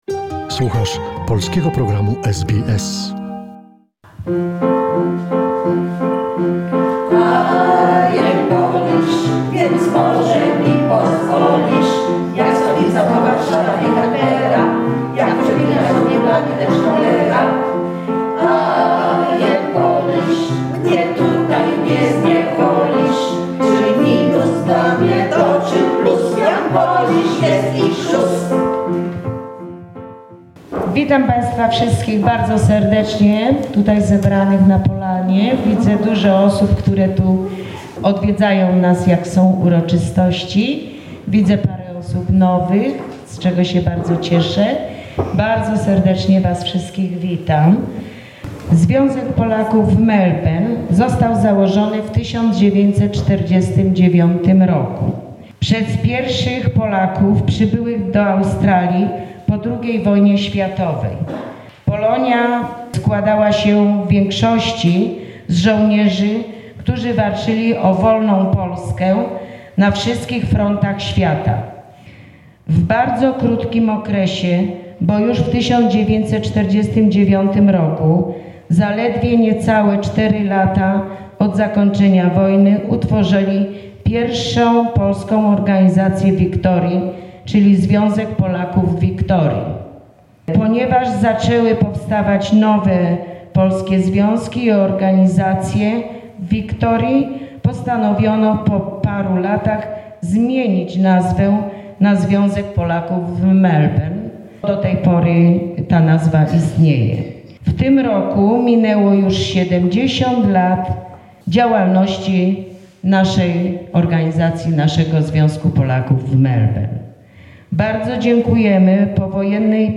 There were speeches, artistic performances, dinner by the fire, a lot of talks and memories...SBS Radio also participated in the event and recorded some moments of the meeting.